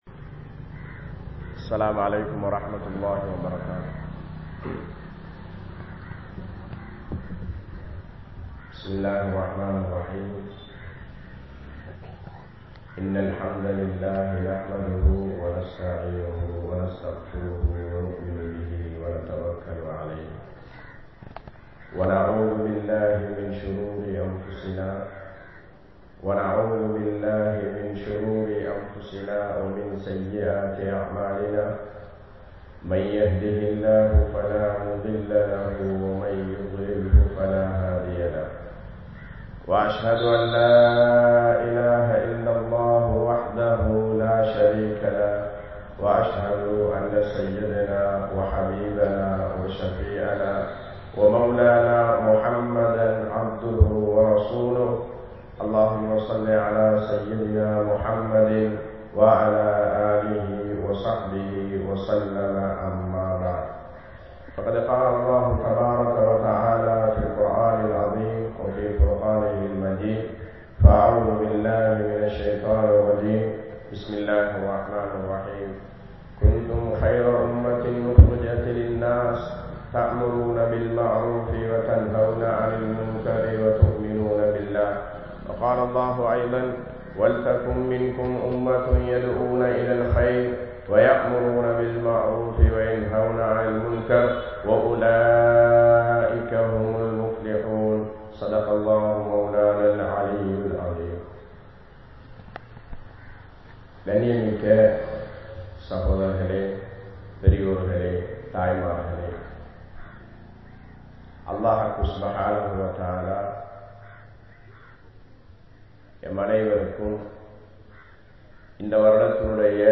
Soathanaihal Ean Varuhintrathu? (சோதனைகள் ஏன் வருகின்றது?) | Audio Bayans | All Ceylon Muslim Youth Community | Addalaichenai